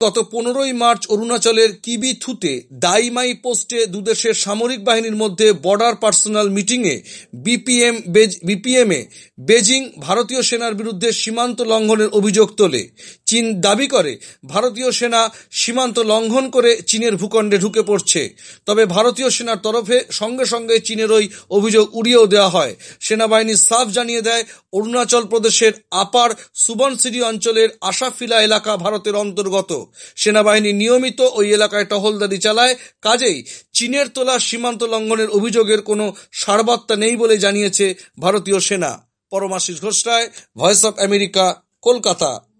কলকাতা থেকে